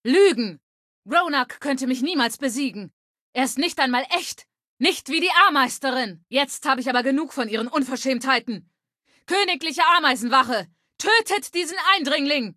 Datei:Femaleadult01default ms02 ms02antorigin2b 000b60cc.ogg
Fallout 3: Audiodialoge